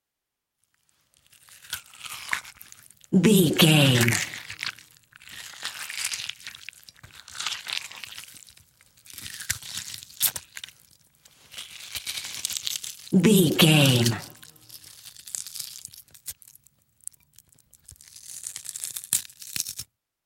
Creature eating rip tear flesh
Sound Effects
ominous
disturbing
horror